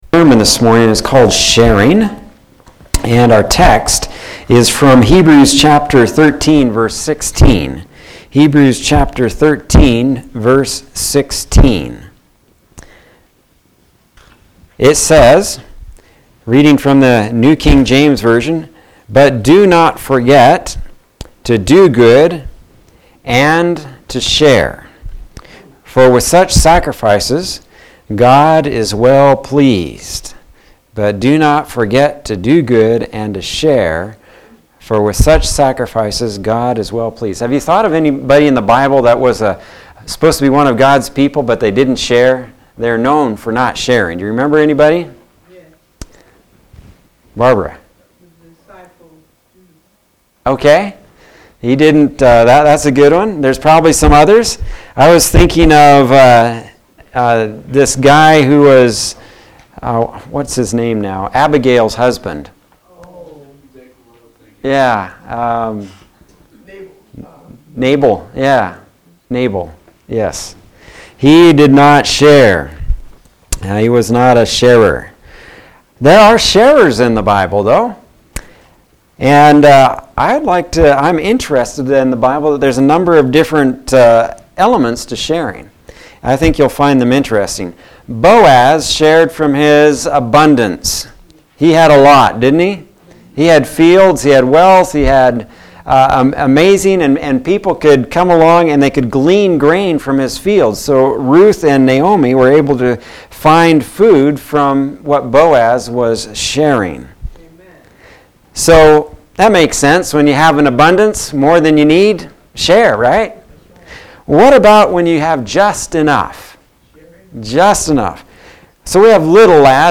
Sermons | La Pine Seventh Day Adventist Church | Page 6